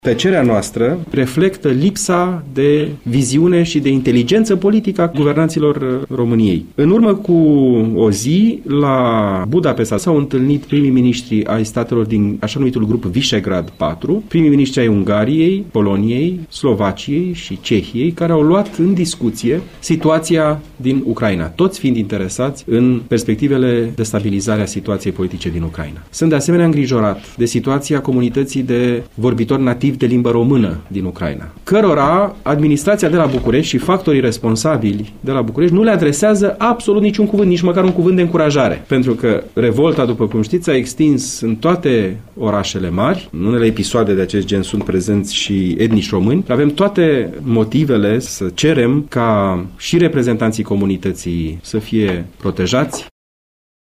”Situaţia din Ucraina devine tot mai periculoasă pentru România”, a afirmat astăzi la Arad preşedintele Partidului Forţa Civică, Mihai-Răzvan Ungureanu. Acesta a mai apreciat într-o conferinţă de că „tăcerea României este profund dăunătoare” în condiţiile în care situaţia din Ucraina tinde să devină „o primăvară egipteană”.